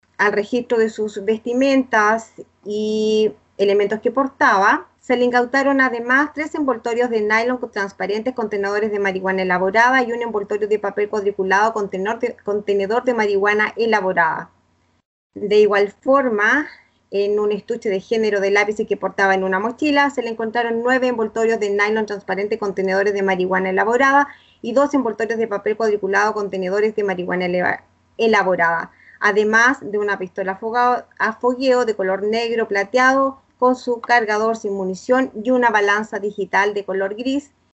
Así lo informó en la formalización, la Fiscal Alejandra Anabalón, quien explicó que la agente reveladora y los imputados se contactaron a través de la aplicación Grindr, donde concertaron la entrega de la droga por un monto de diez mil pesos.